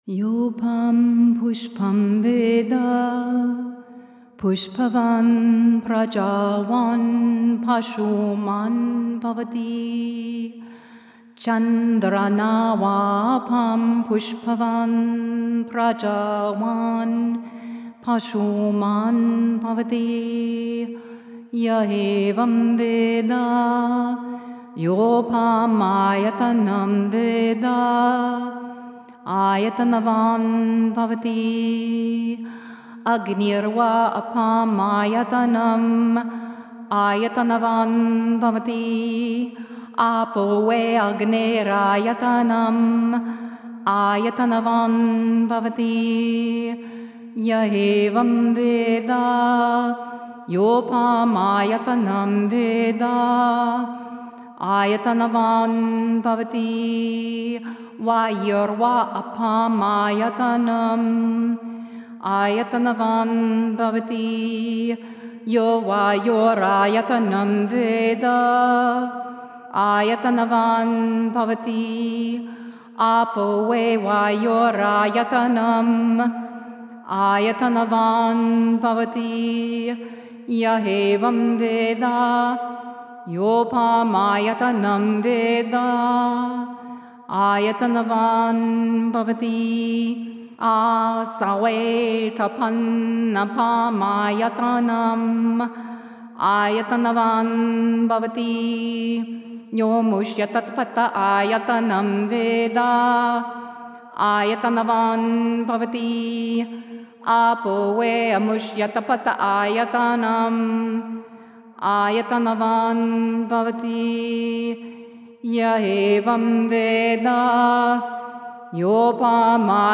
This is a challenging Mantra to learn, the sounds are foreign to the western ear.
The sounds came from the bottom of the throat and back of nose, the tongue needed to be on the roof of my mouth at times, on the back of my teeth.